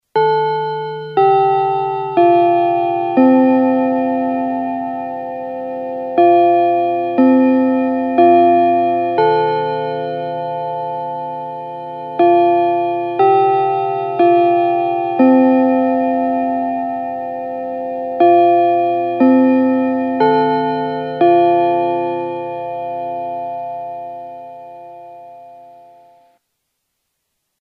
Classroom Chime Editied